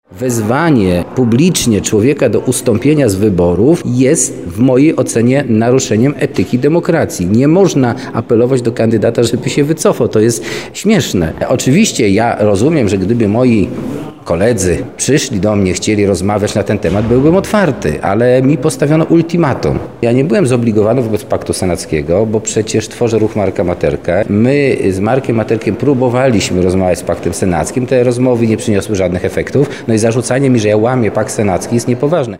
W trakcie przerwy w obradach sesji zapytaliśmy także Marcina Nowaka, co myśli o działaniach radnych.